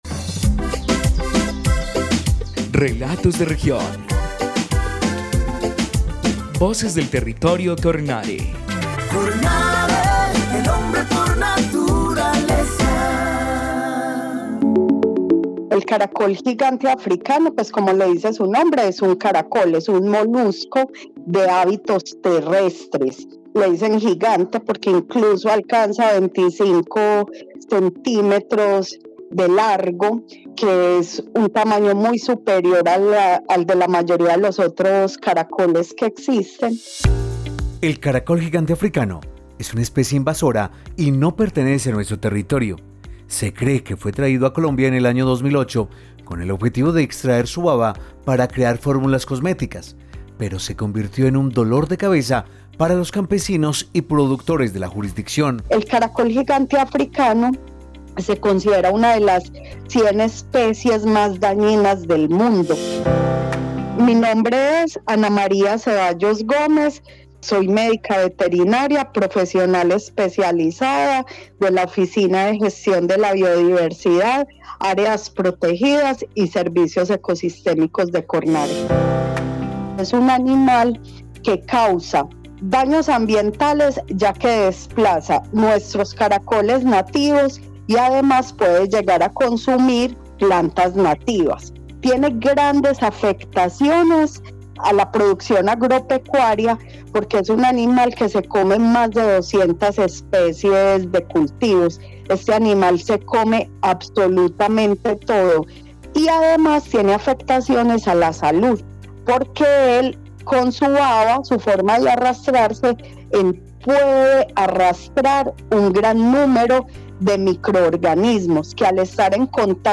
Programa de radio 2024